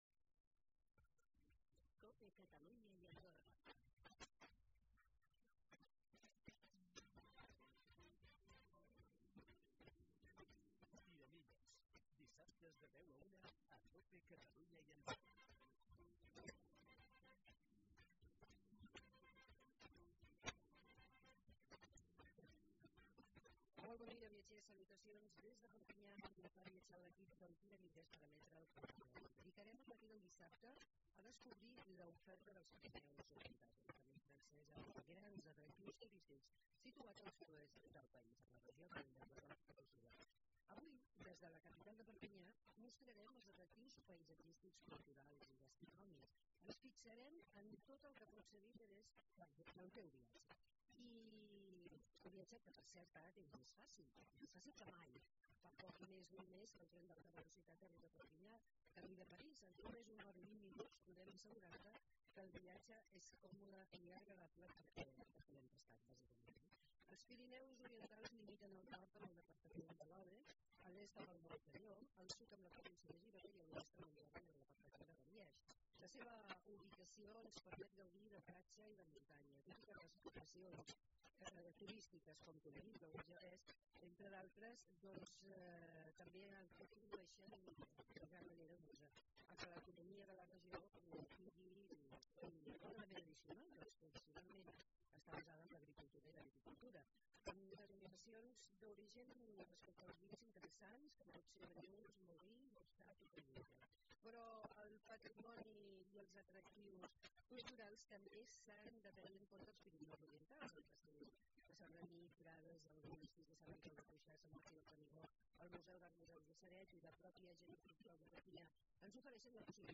Programa especial desde Perpiñan (Primera hora, primera parte)